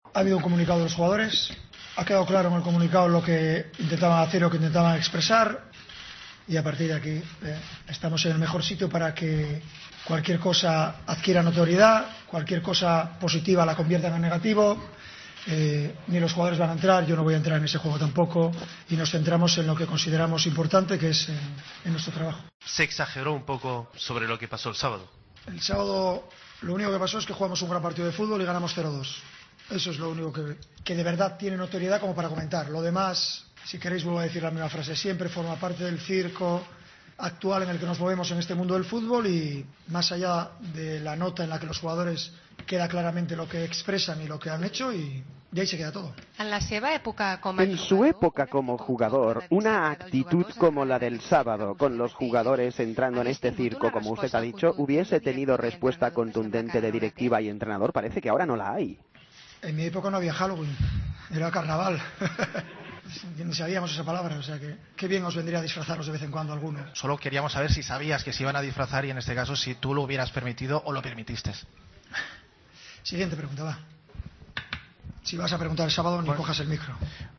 El técnico azulgrana defendió así a sus jugadores en la rueda de prensa previa al encuentro contra el Bate Borisov: "El sábado lo único que pasó es que jugamos un muy buen partido de fútbol y ganamos 0-2. Lo demás forma parte del circo, en mi época no existía 'Halloween', era carnaval. ¡Qué bien vendría disfrazaros a algunos!".